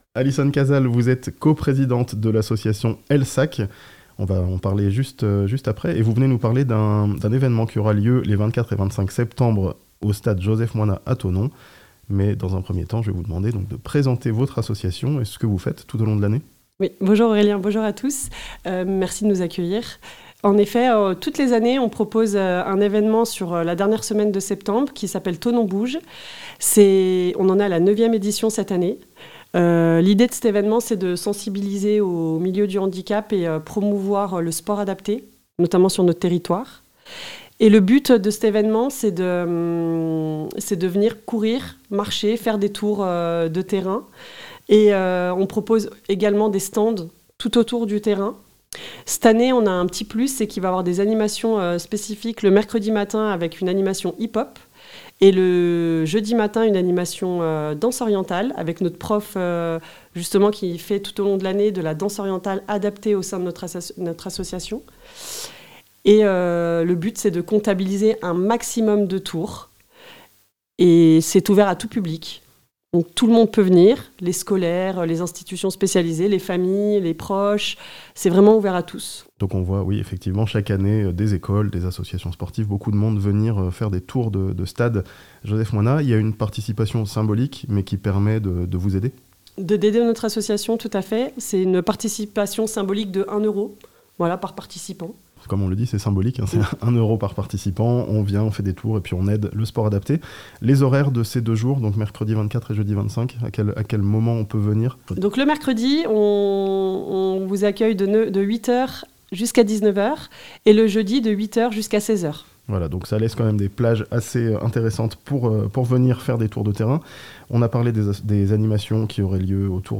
Les 24 et 25 septembre, Thonon va bouger pour le sport adapté (interview)